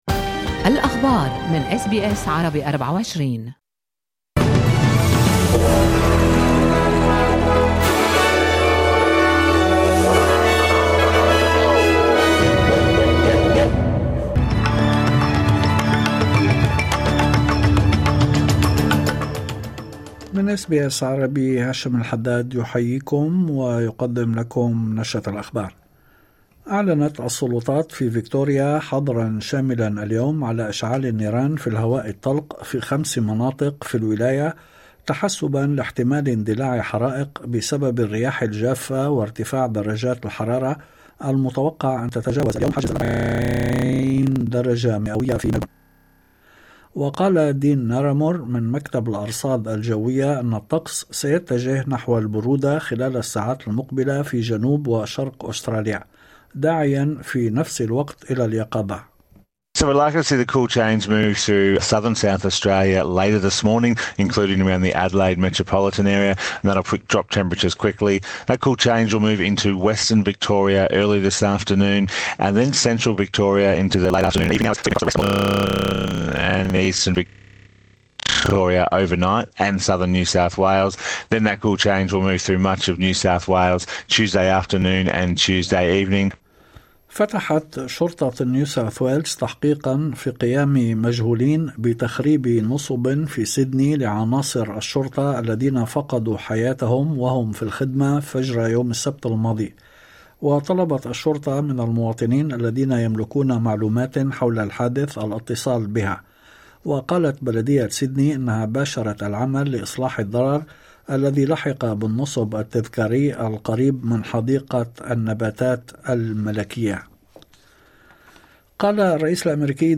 نشرة أخبار الظهيرة 27/01/2025